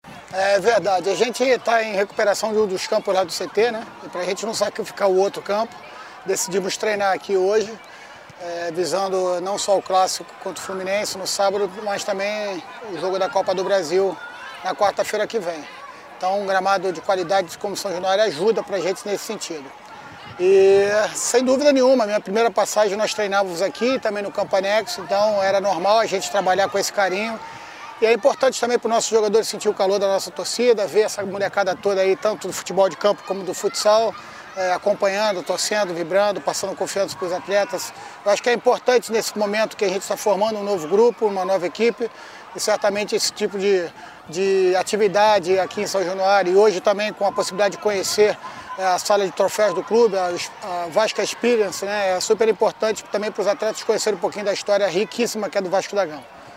O técnico Zé Ricardo falou da opção de trabalhar em São Januário na preparação da equipe para os dois próximos jogos com o Fluminense no sábado (26/2), às 17 horas no Estádio Nilton Santos pelo Cariocão, e na quarta-feira (02/3), às 2130h, com a Ferroviária em Araraquara, interior de São Paulo, pela Copa do Brasil.